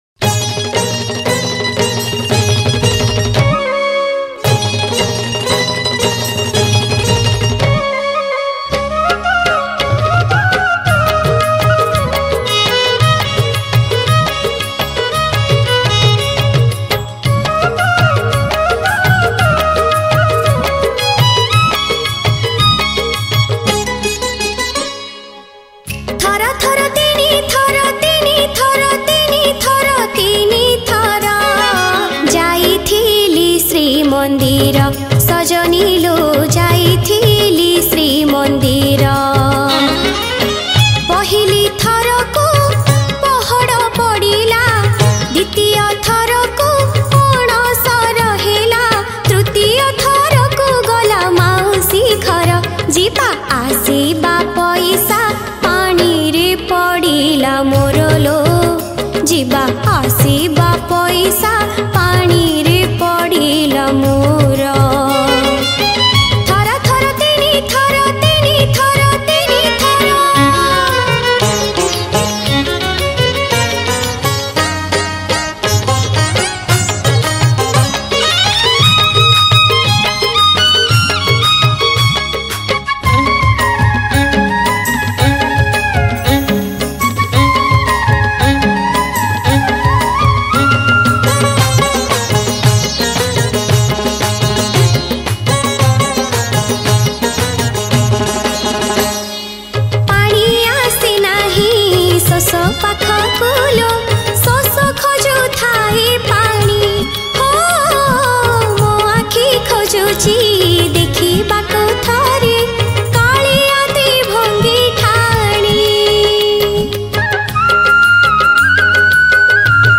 Category : Odia Bhajan Song 2022